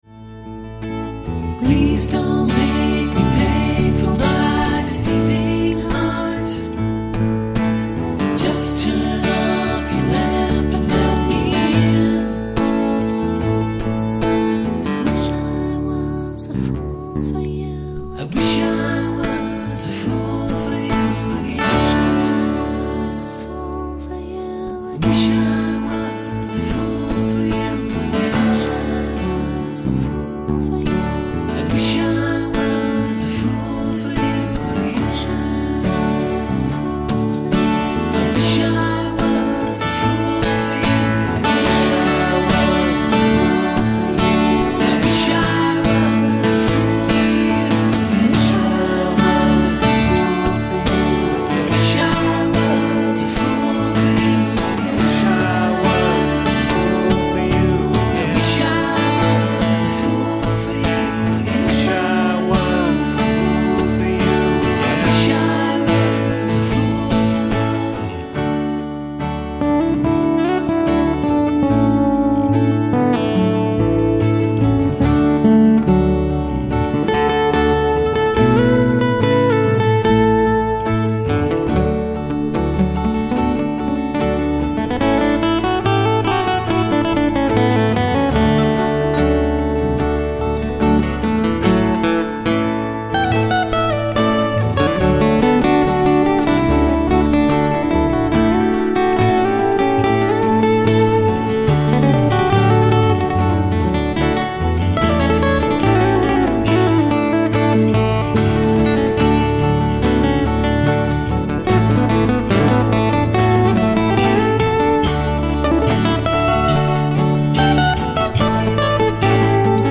acoustic guitar, bvox
acoustic guitar, vox